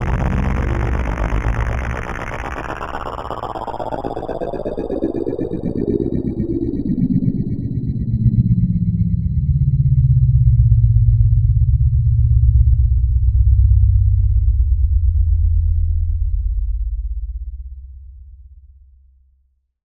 Index of /90_sSampleCDs/Club_Techno/Sweeps
Sweep_1_C2.wav